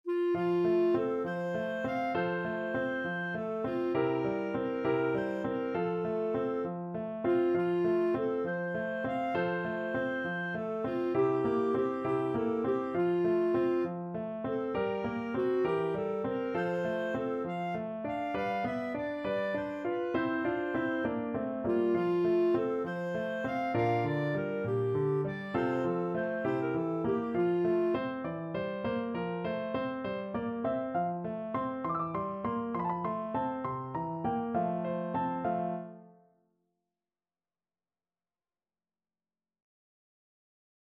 Clarinet
F major (Sounding Pitch) G major (Clarinet in Bb) (View more F major Music for Clarinet )
~ = 100 Fršhlich
6/8 (View more 6/8 Music)
Classical (View more Classical Clarinet Music)